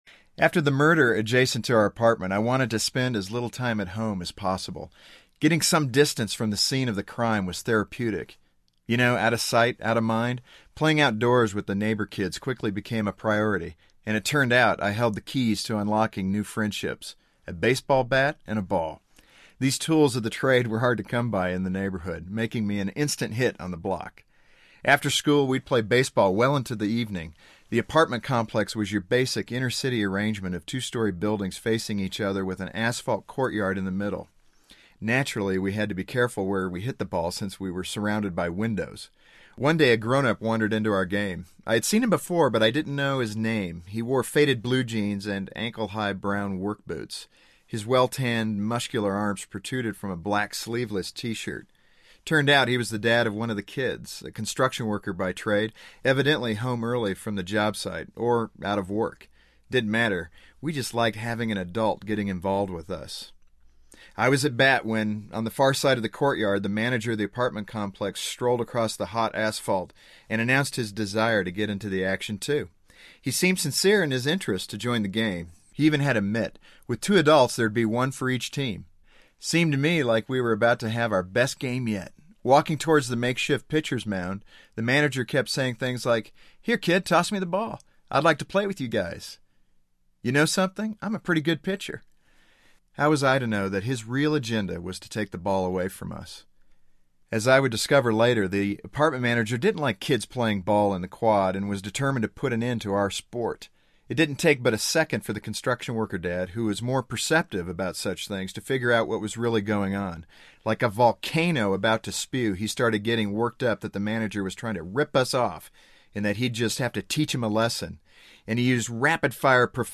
Finding Home Audiobook
5.3 Hrs. – Unabridged